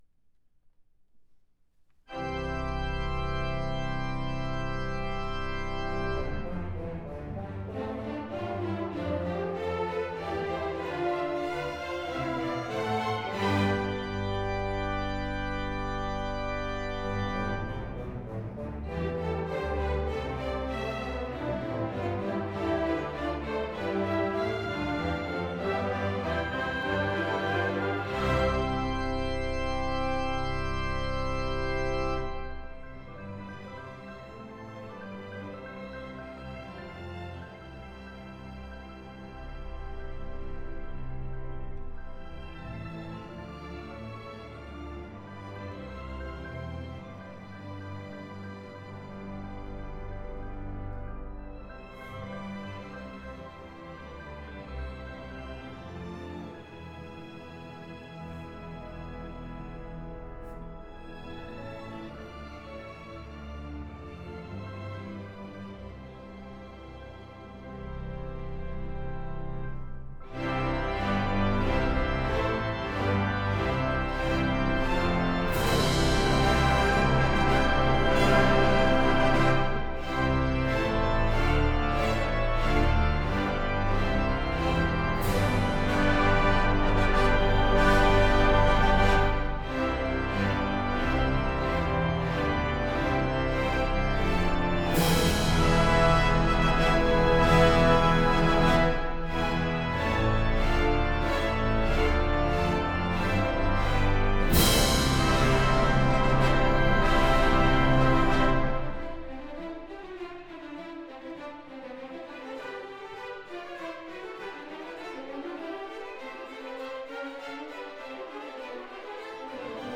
This is definitely on-topic for this board- the description reads, in part: ....... the “tuba” division, a set of pipes driven by a higher wind pressure and designed specifically to accompany a full orchestra at musical climaxes."